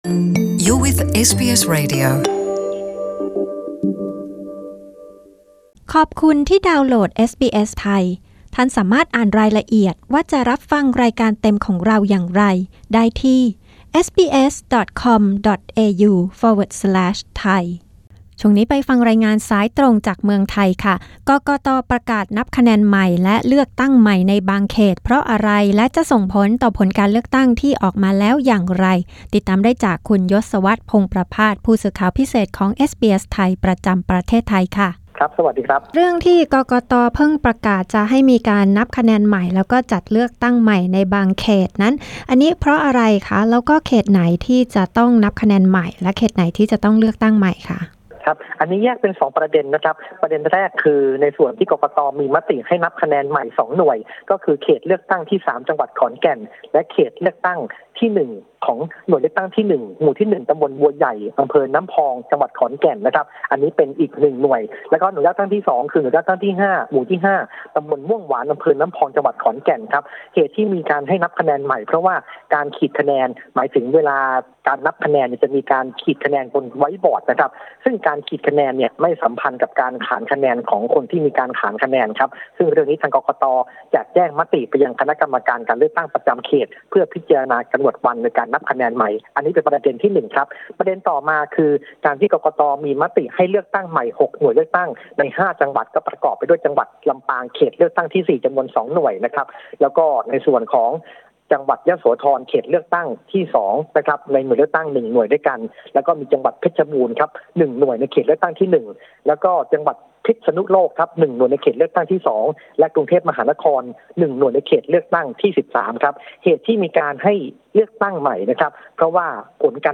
ประกาศให้มีการเลือกตั้งใหม่ในบางเขต และนับคะแนนใหม่บางเขต เพราะอะไร และจะส่งผลต่อผลการเลือกตั้งครั้งนี้อย่างไร รายงานนี้ออกอากาศเมื่อคืนวันพฤหัสบดี ที่ 4 เม.ย. ในรายการวิทยุเอสบีเอส ไทย เวลา 22.00 น. เรื่องราวที่เกี่ยวข้อง การเมืองไทย: สิ้นหวังหรือมีหวัง?